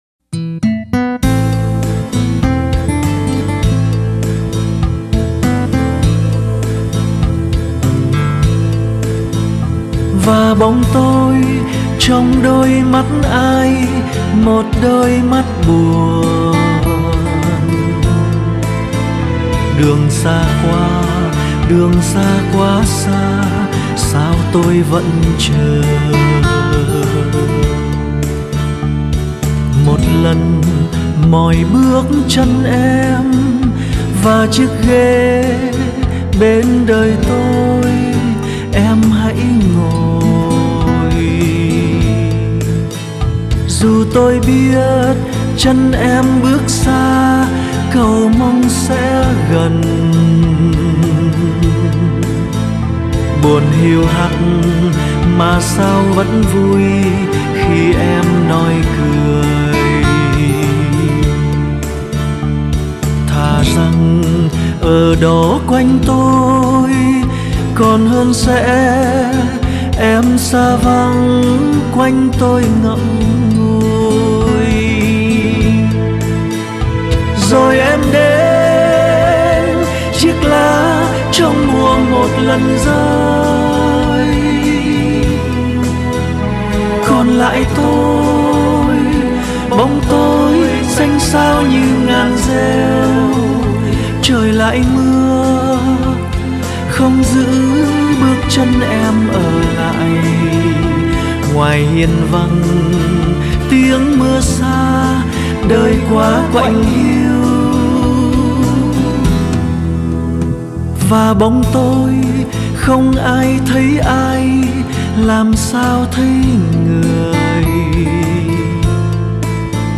Tác giả trình bày